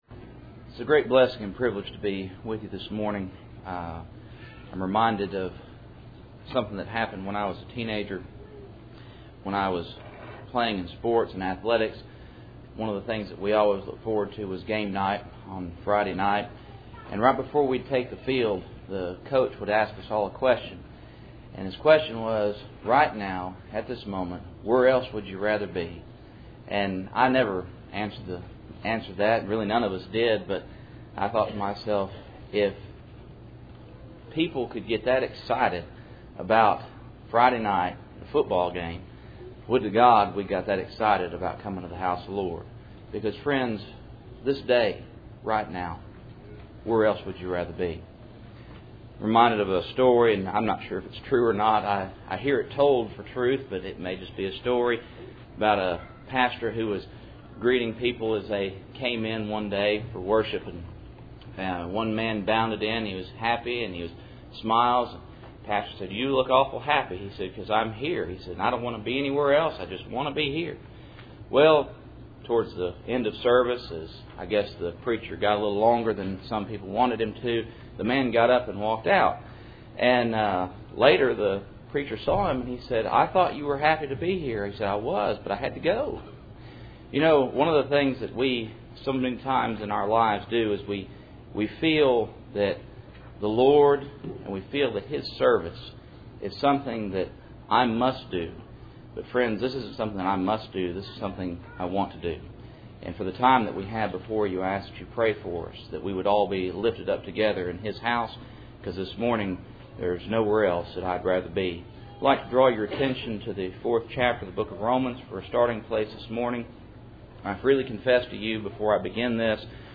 Romans 4:1-3 Service Type: Cool Springs PBC Sunday Morning %todo_render% « I Peter 5:5-7